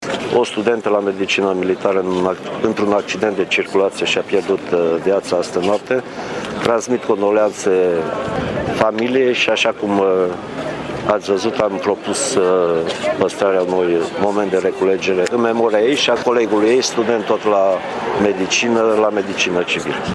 Ministrul Apărării Naționale, Mircea Dușa, prezent la Tîrgu-Mureș la ceremoniile dedicate împlinirii a 25 de ani de la înființarea catedrei de medicină militară la UMF, a propus păstrarea unui moment de reculegere în memoria celor decedați.